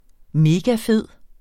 megafed adjektiv Bøjning -t, -e Udtale [ ˈmeːgaˈfeð ] Betydninger enormt god, smart eller dejlig SPROGBRUG uformelt Synonymer herrefed hammerfed Ungerne synes, det er en megafed måde at rejse på.